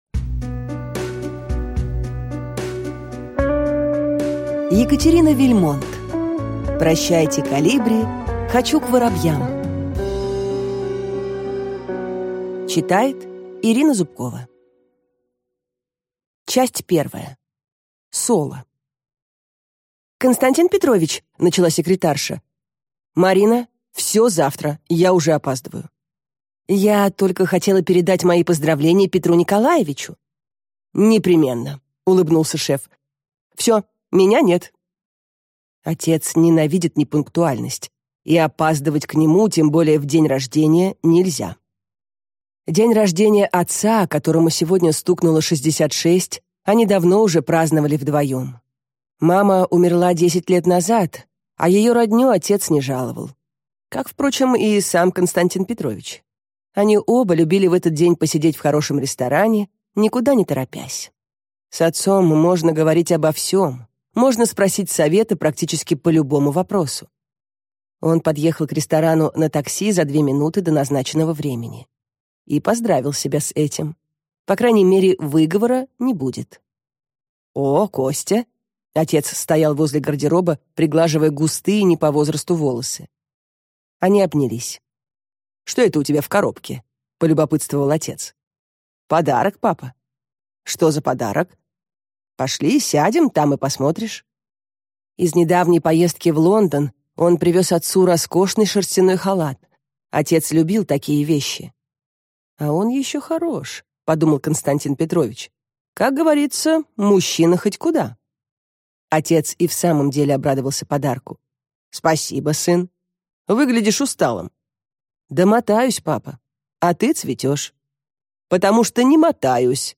Аудиокнига Прощайте, колибри, хочу к воробьям!